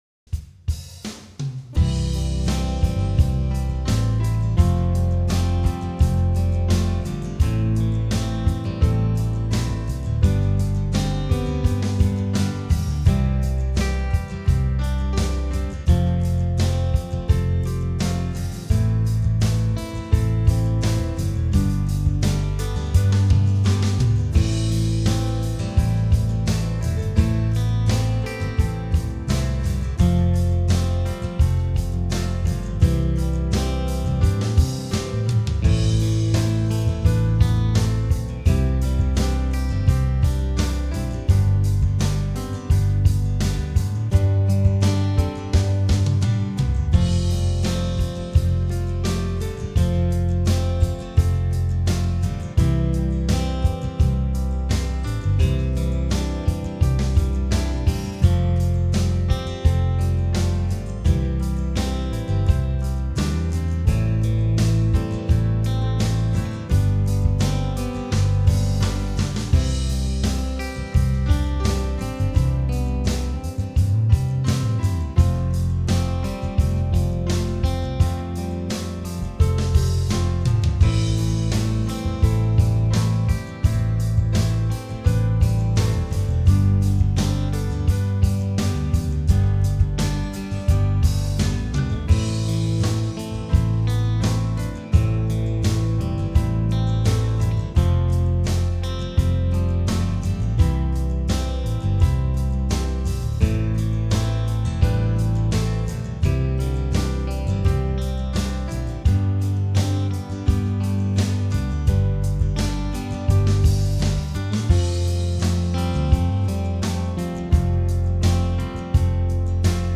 Home > Music > Pop > Smooth > Medium > Dreamy